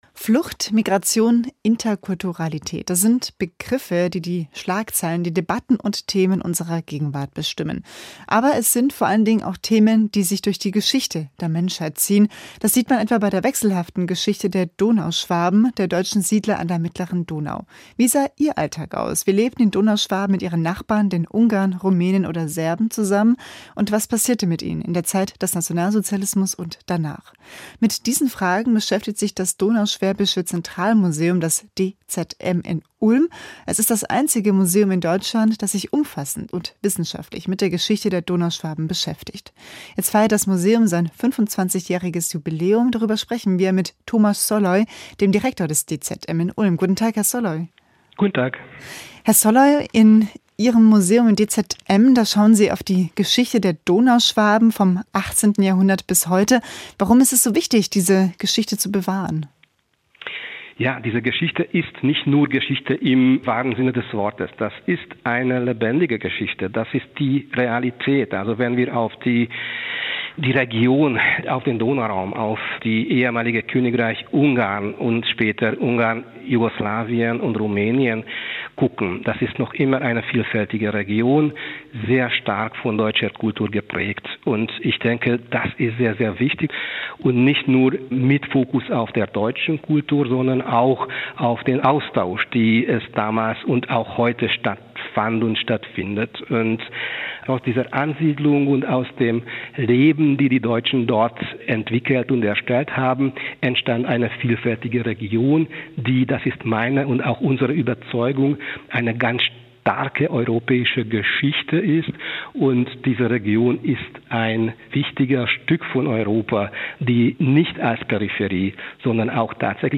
Das Interview führte
Interview mit